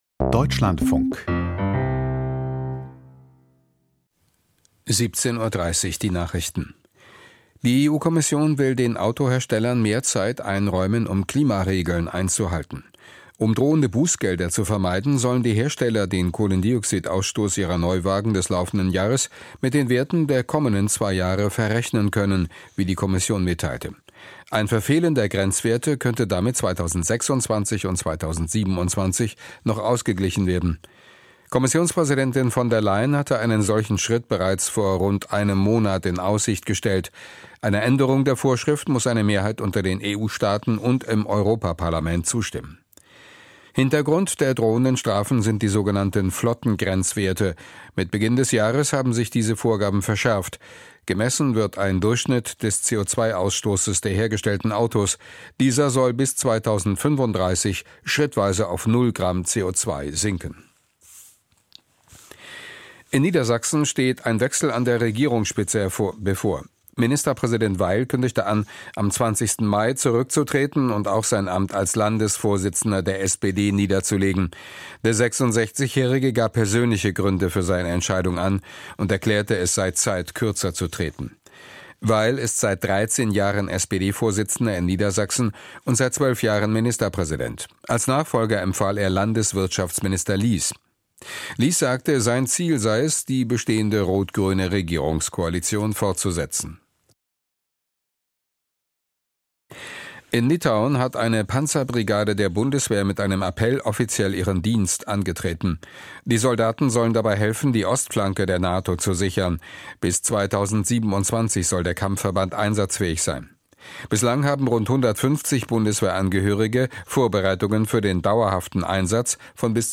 Die Deutschlandfunk-Nachrichten vom 01.04.2025, 17:30 Uhr